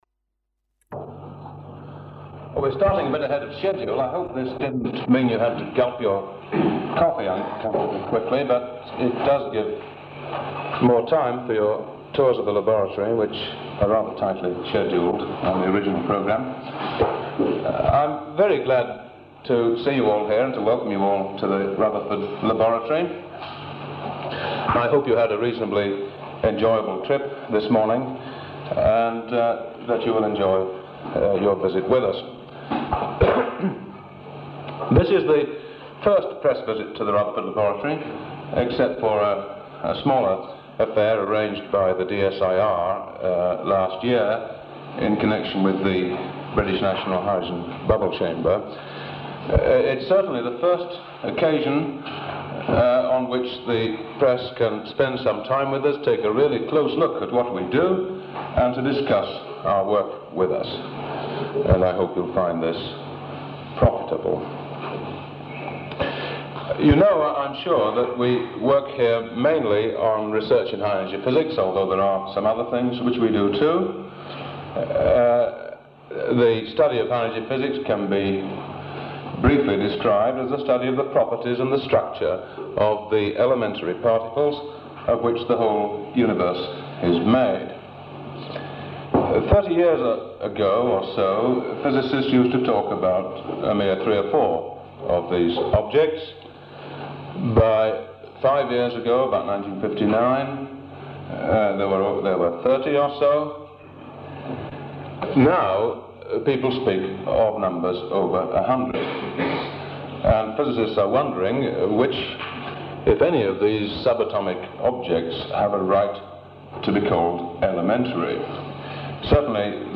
The last nine minutes appear to be general machine room noises; perhaps left over from an earlier test of the audio equ